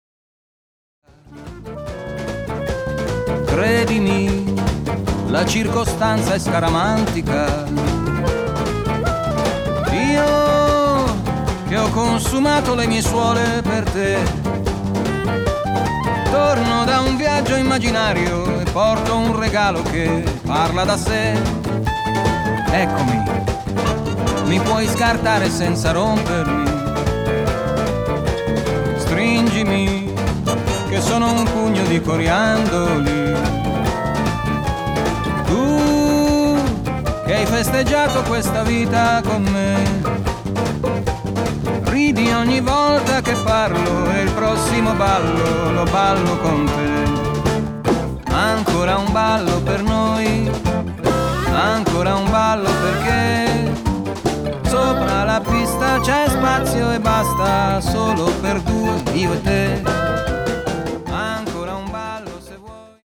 chitarra e voce